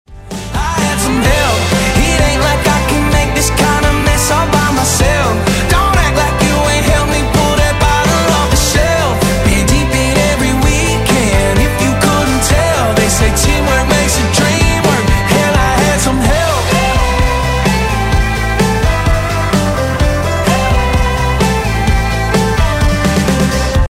K-Pop File format